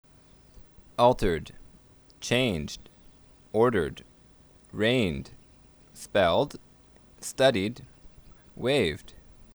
A Guide to Pronunciation
To listen to the above verbs ending in [d],